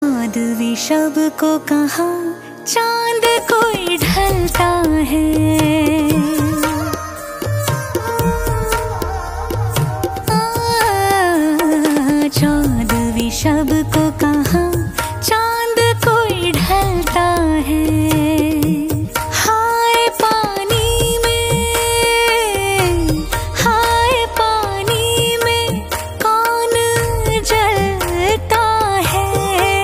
Hindi Songs
A Melodious Fusion